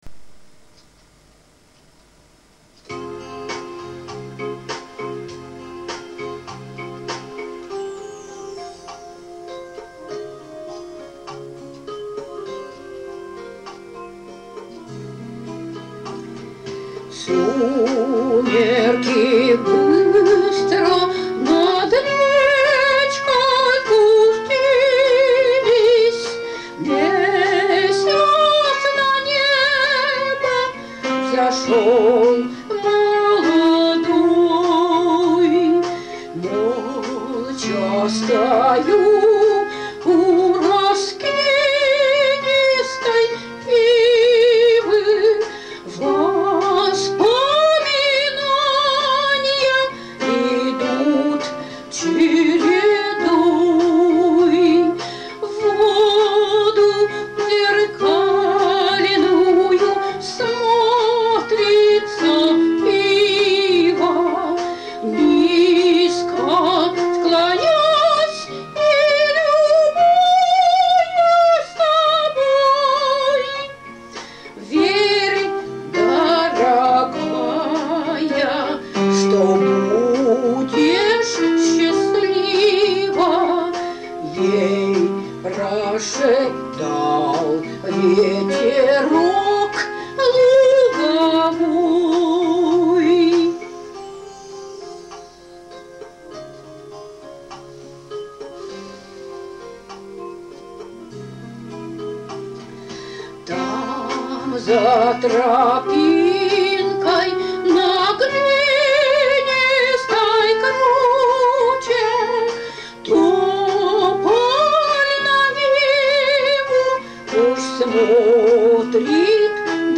Синтезатор
• Жанр: Романс